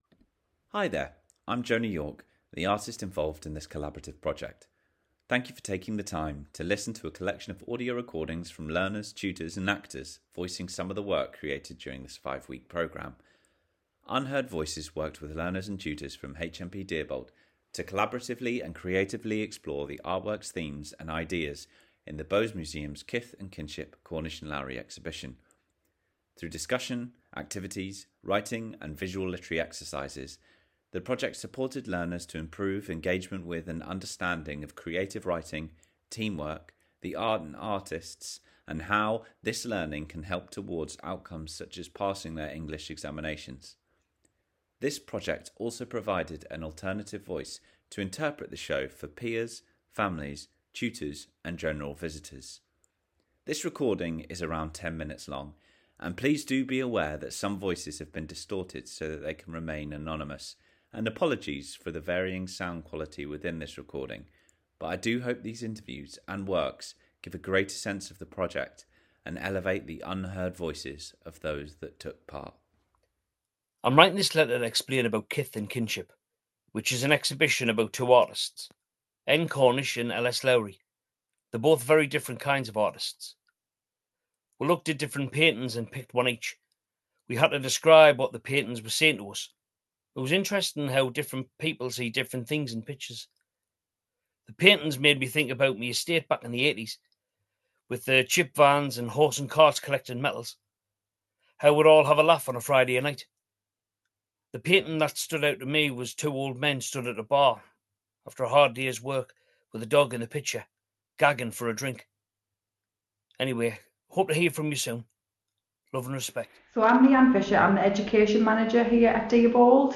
Over 15 male learners from HMP Deerbolt worked to create a zine and soundscape.
SOUNDSCAPE